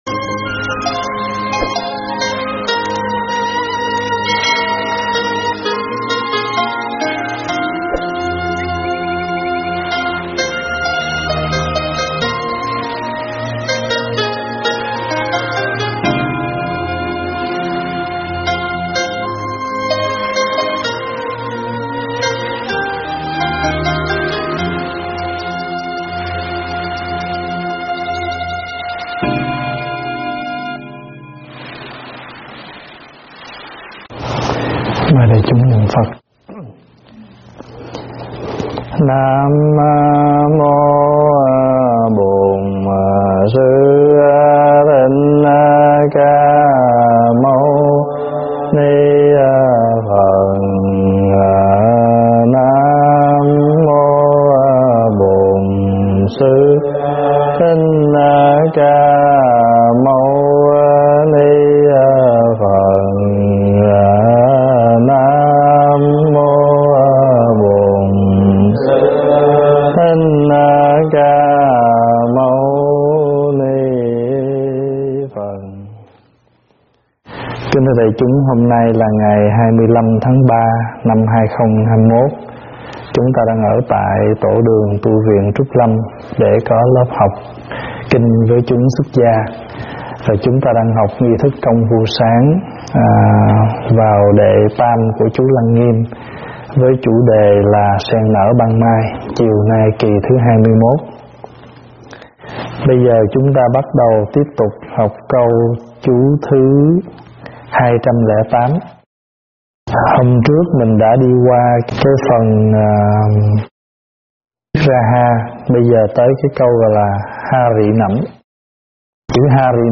Thuyết pháp Sen Nở Ban Mai 21
giảng tại Tv.Trúc Lâm